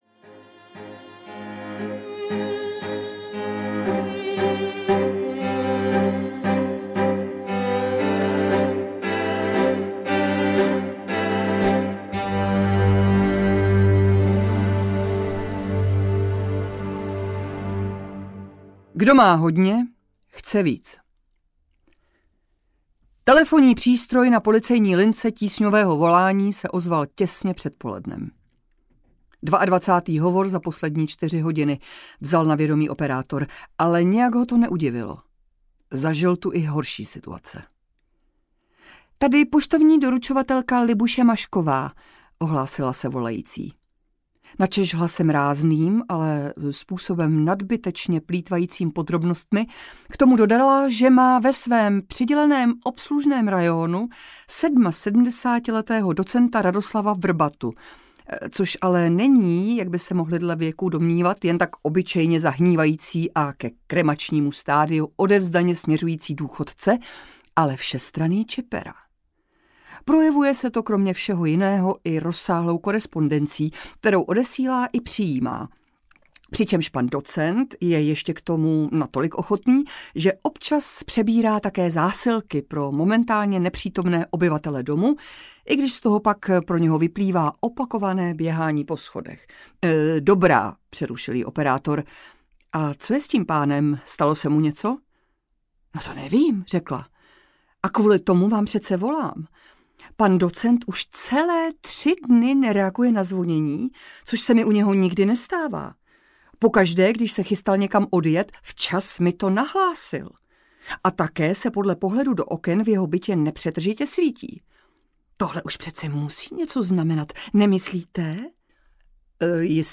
Doteky bezmoci audiokniha
Ukázka z knihy